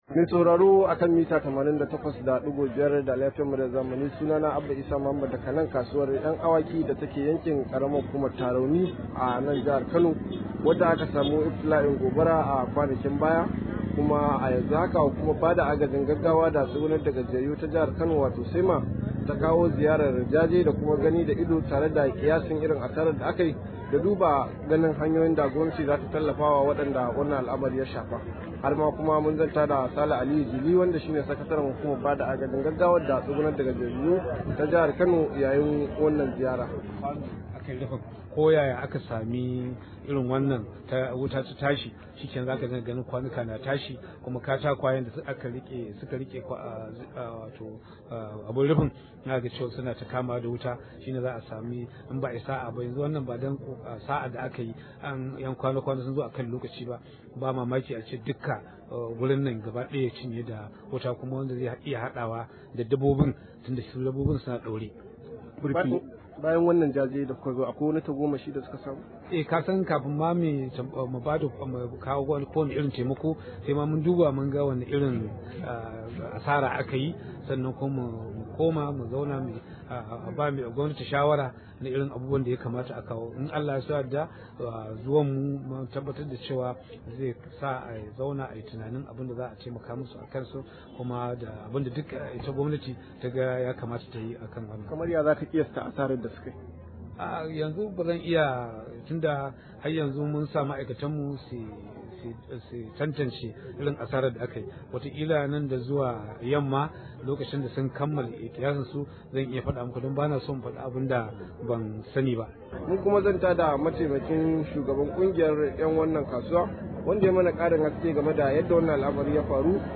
Rahoto: Ganduje zai tallafawa kasuwar ‘Yan Awaki kan iftila’in gobara – SEMA